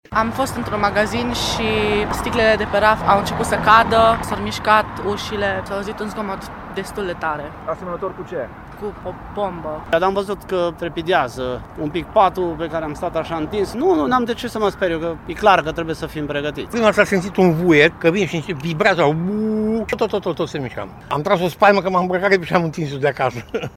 a.-voxuri.mp3